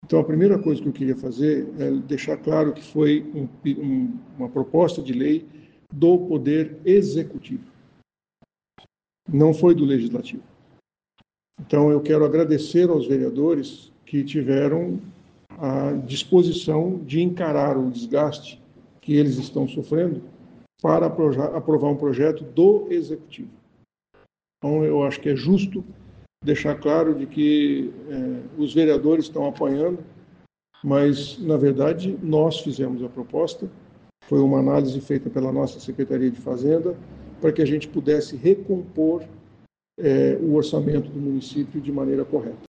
Prefeito Sílvio Barros reúne a imprensa para tirar dúvidas sobre IPTU